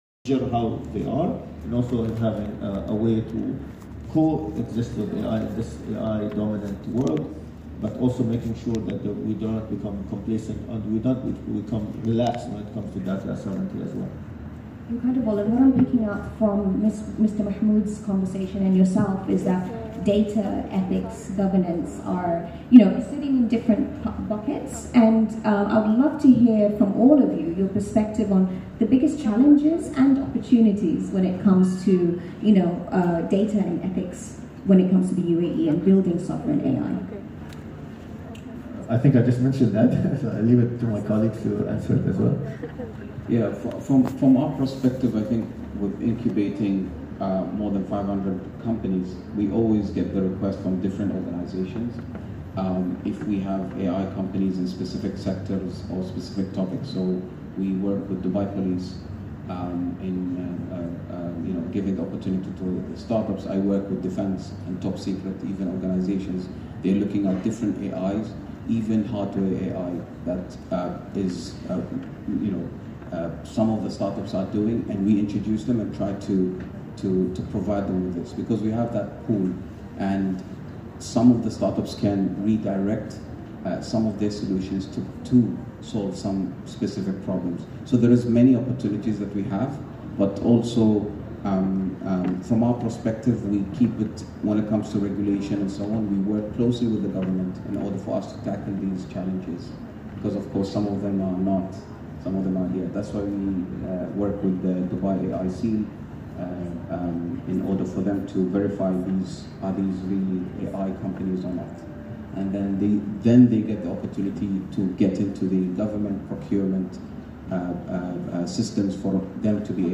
Listen to the panel discussion from the middle below: